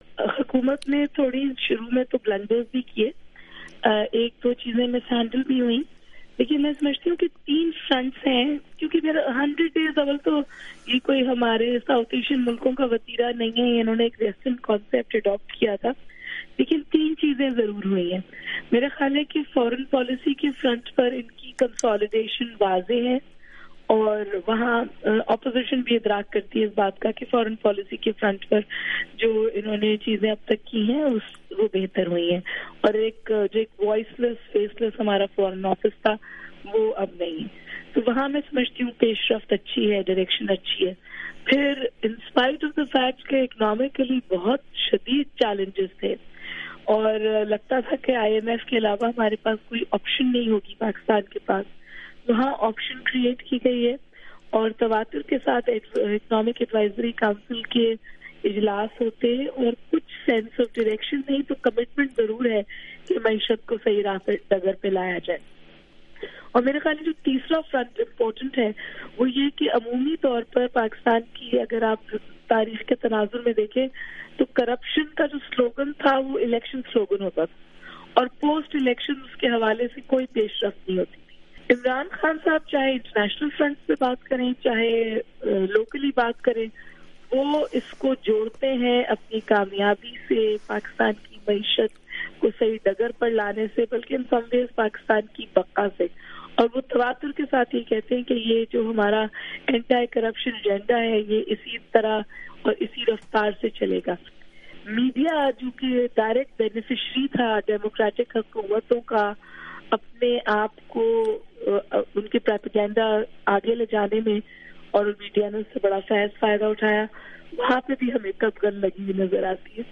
دو معروف سیاسی تجزیہ کاروں